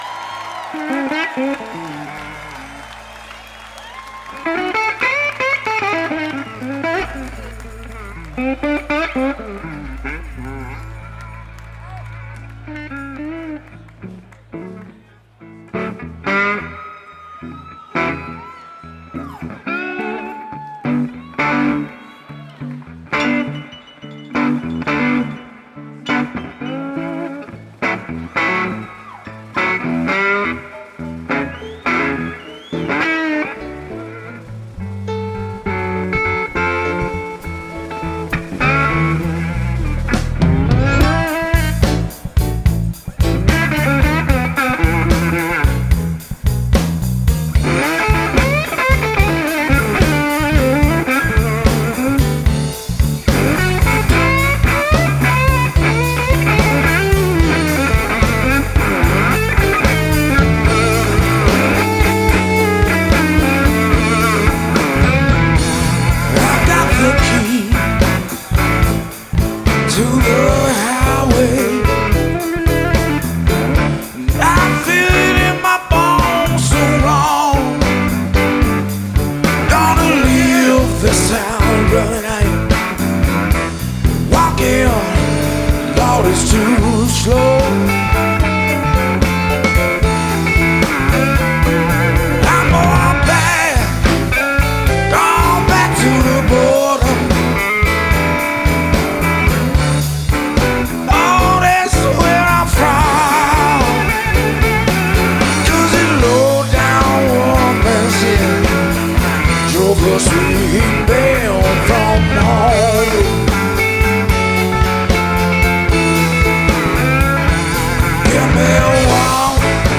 passionate guitar play
cover